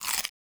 Crunch Bite Item (2).wav